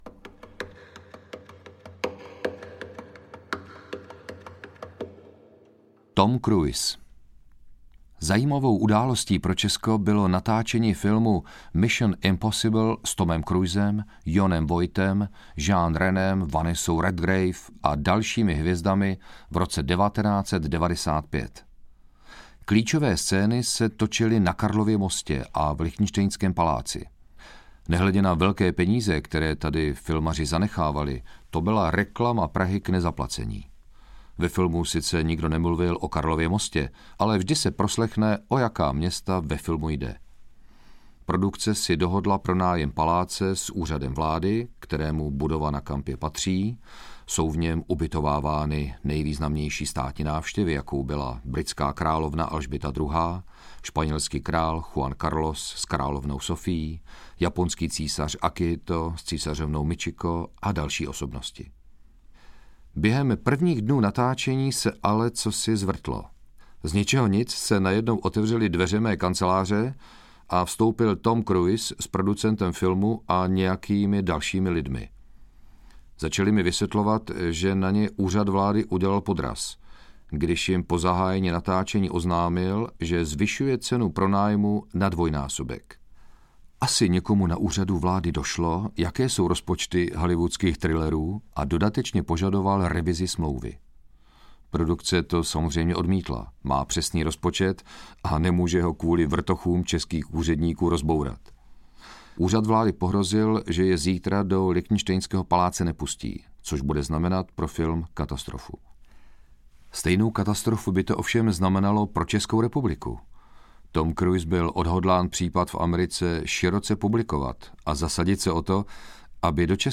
Ukázka z knihy
• InterpretLadislav Špaček